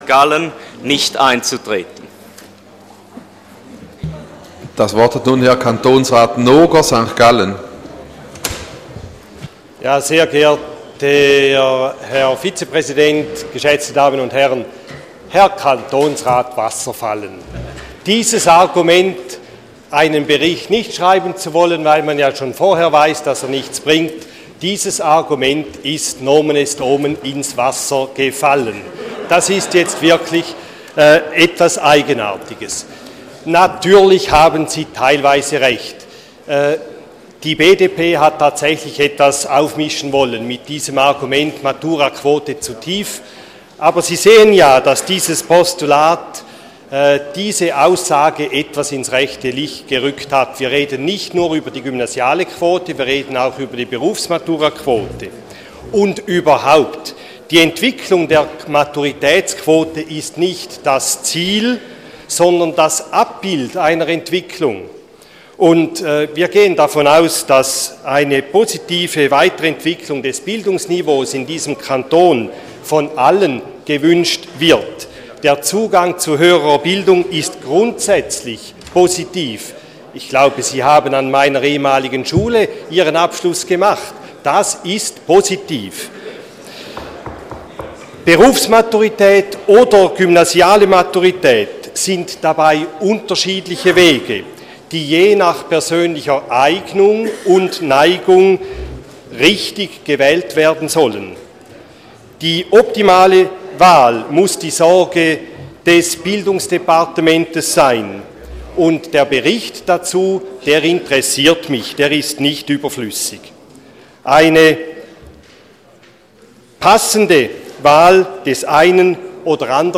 24.2.2015Wortmeldung
Session des Kantonsrates vom 23. bis 25. Februar 2015